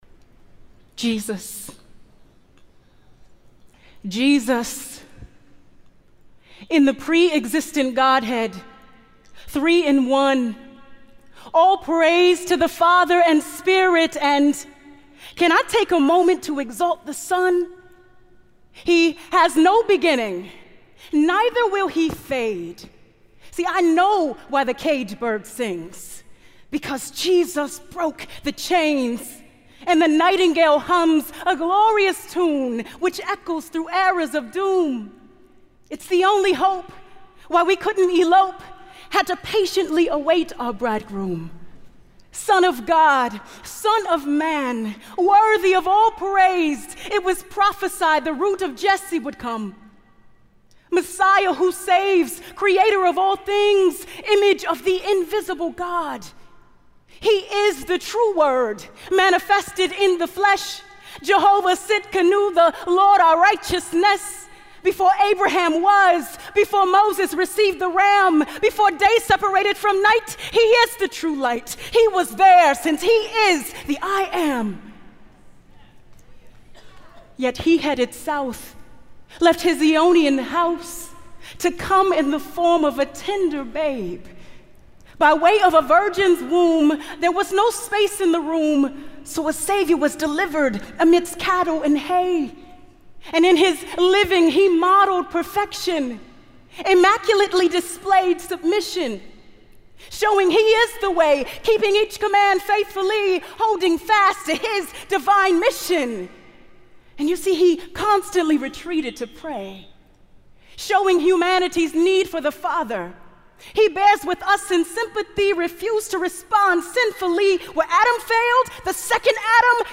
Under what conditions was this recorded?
Amazed by His Wonder (Spoken Word Poetry) | True Woman '14 | Events | Revive Our Hearts